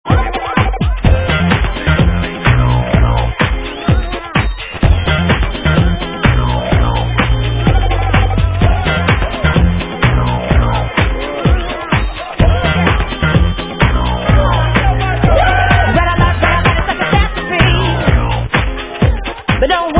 sledovat novinky v oddělení Dance/House